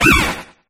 flapple_ambient.ogg